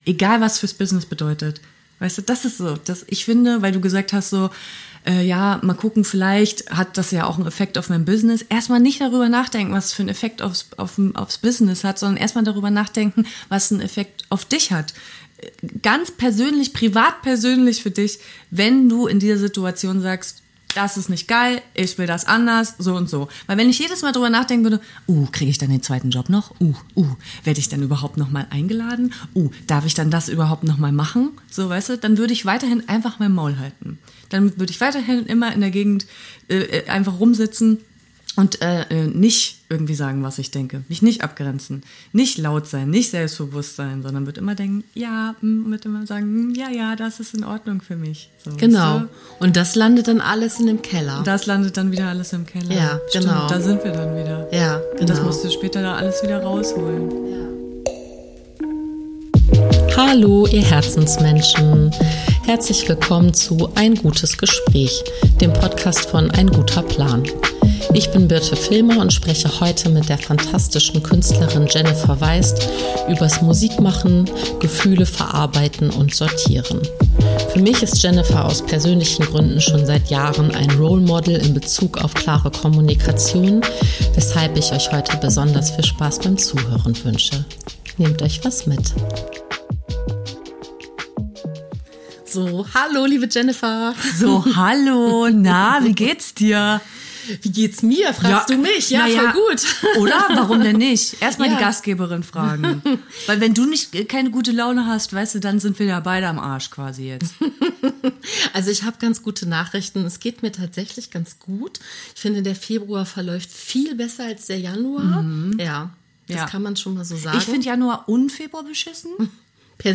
Wie man Grenzüberschreitungen rückmeldet, um sich vor übergriffigem Verhalten zu schützen und sich in radikaler Akzeptanz übt, all das hört ihr in diesem empowernden, kraftvollen und mutmachenden Gespräch mit Jennifer Weist.